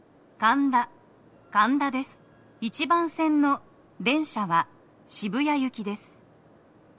スピーカー種類 TOA天井型
🎵到着放送
足元注意喚起放送の付帯は無く、フルは比較的鳴りやすいです。